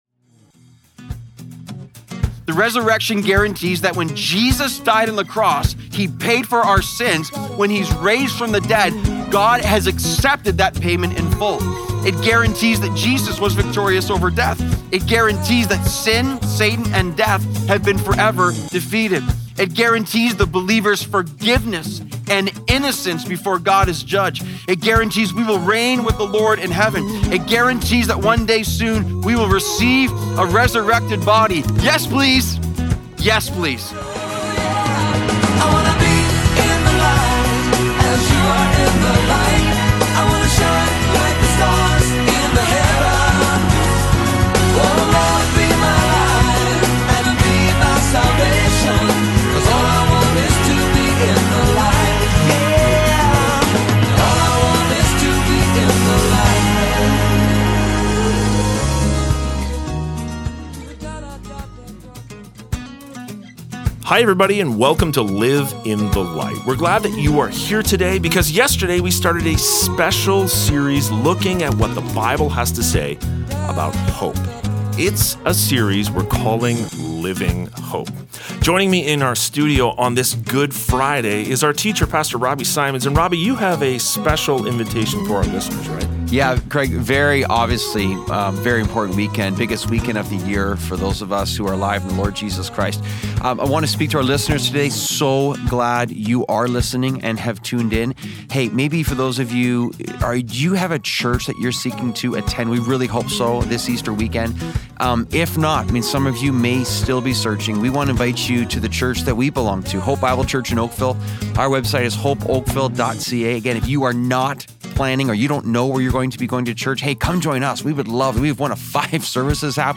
Live in the Light Daily Broadcast Living Hope P2 Apr 07 2025 | 00:29:30 1x 00:00 / 00:29:30 Subscribe Share Apple Podcasts Spotify Overcast RSS Feed Share Link Embed